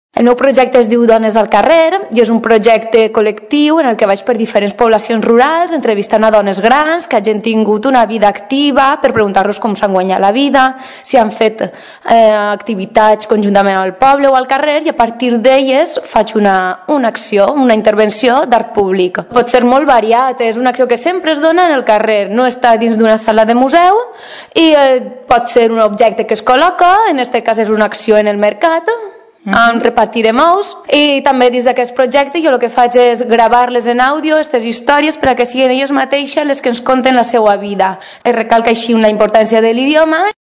Així ho explica, en declaracions a Ràdio Tordera.